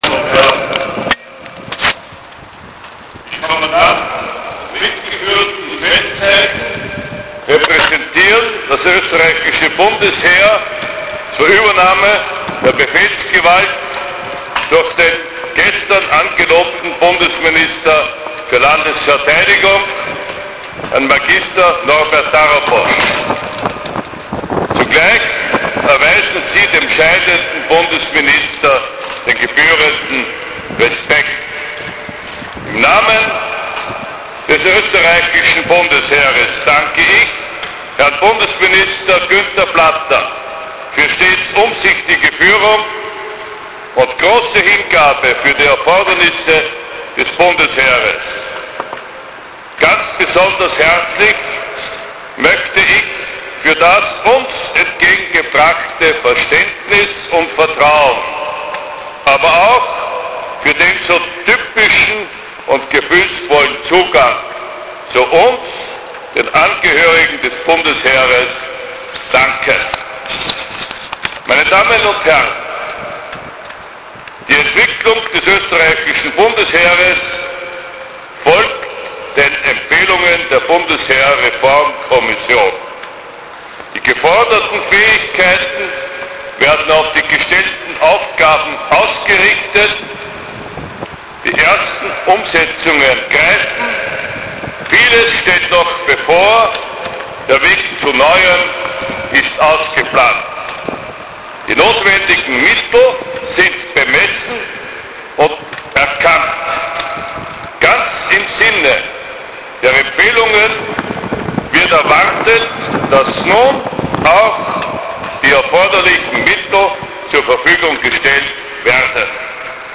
Generalstabschef Mag.Roland Ertl
Bundesminister aD Günther Platter
Bundesminister Mag. Norbert Darabos